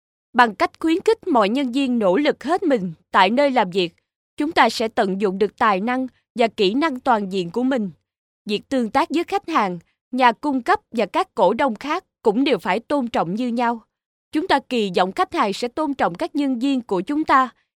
Vietnamese female voice over